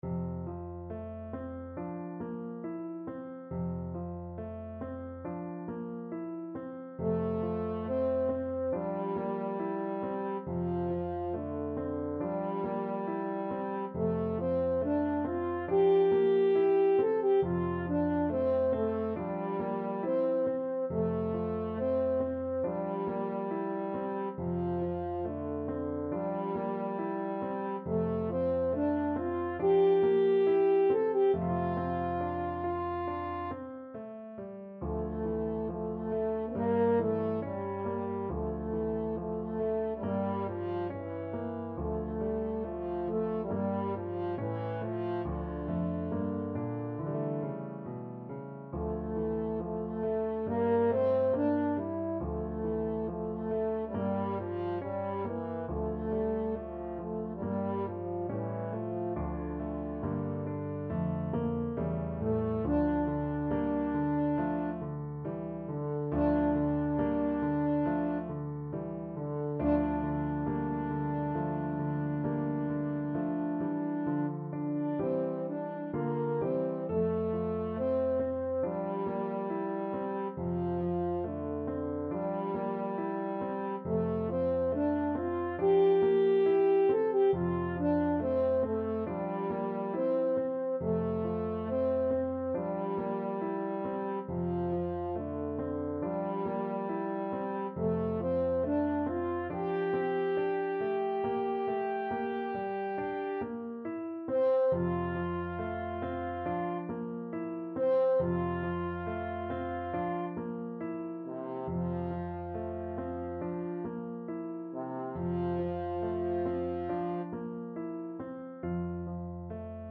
French Horn
F major (Sounding Pitch) C major (French Horn in F) (View more F major Music for French Horn )
~ =69 Poco andante
4/4 (View more 4/4 Music)
Classical (View more Classical French Horn Music)